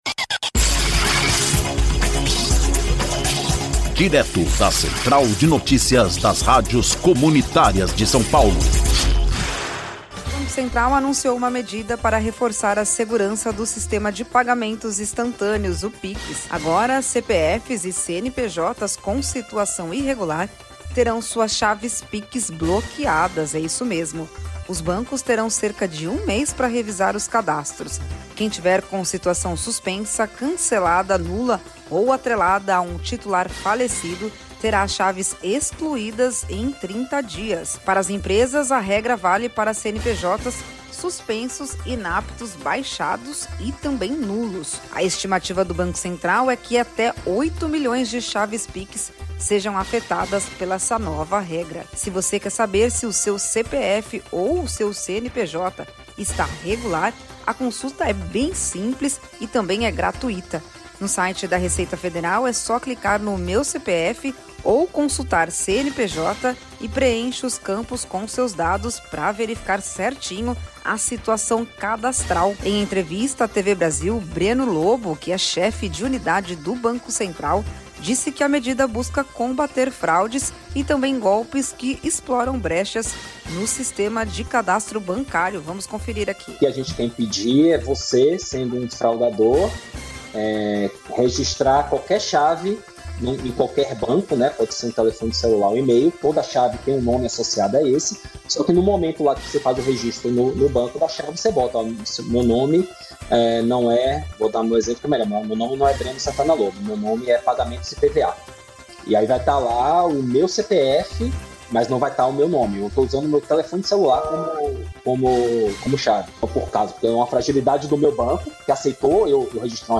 Ouça a notícia: Banco Central bloqueia chaves Pix de CPFs e CNPJs irregulares